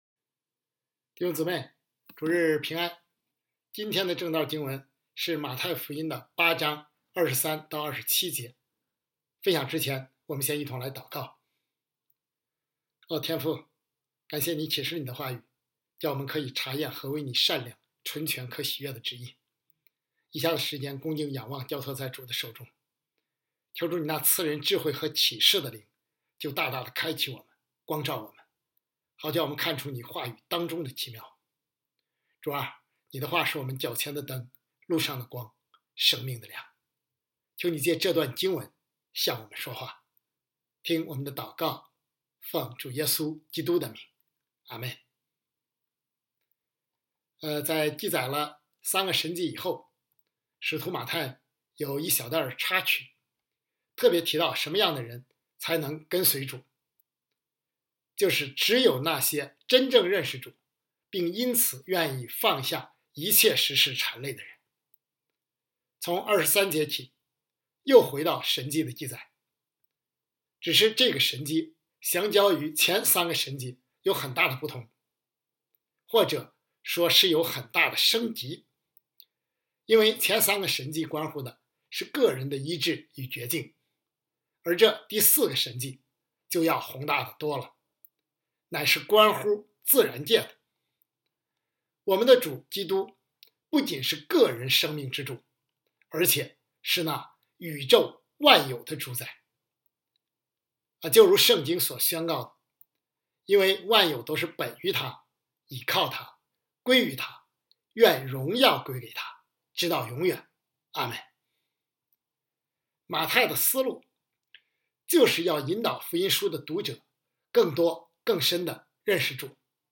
北京守望教会2025年2月16日主日敬拜程序